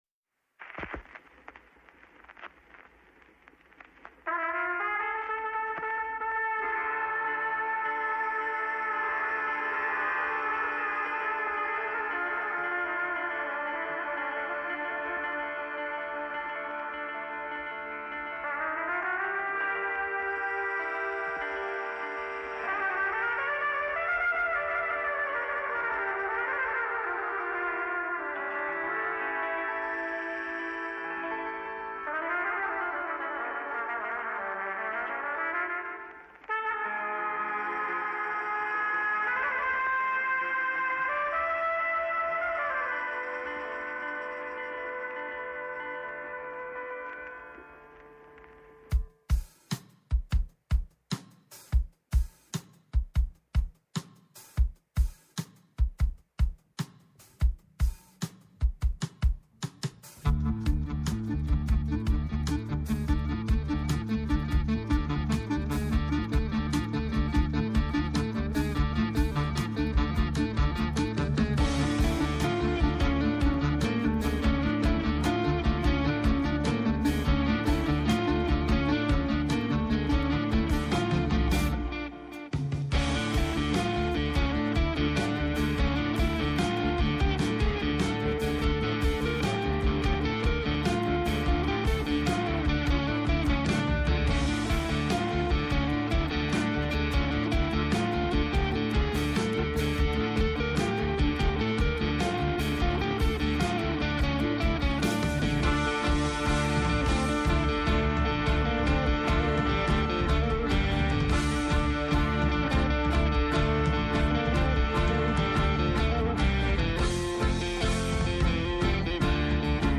Ηλεκτρικές κιθάρες που μας συνδέουν με το πάλαι ποτέ Ελληνικό Ροκ του 90’, παραδοσιακά πνευστά που ξεπροβάλουν μέσα από ψυχεδελικά τοπία, ζωηροί ρυθμοί που υποστηρίζουν τους έντονα κοινωνικούς στίχους και καταλήγουν να είναι η λοξή ματιά του σχήματος για όσα συμβαίνουν γύρω τους.